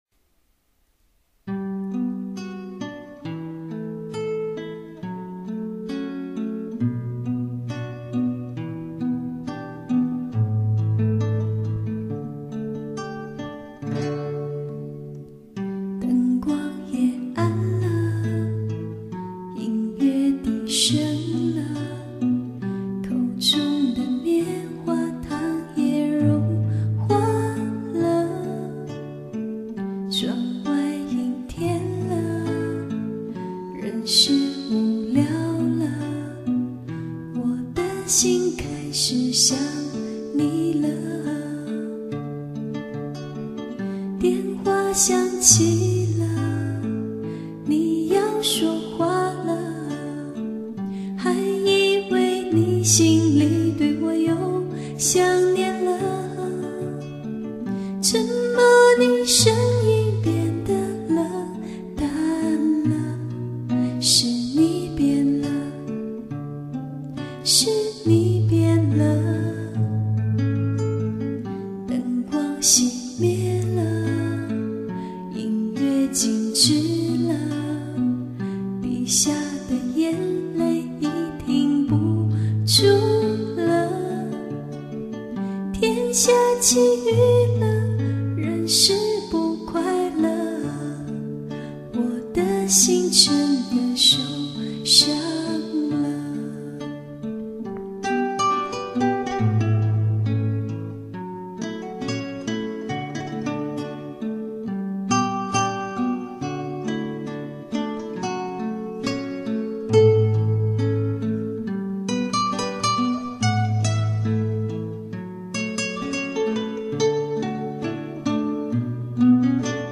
第一次翻唱,,不太会弄效果,,希望能得到各位朋友的指点~~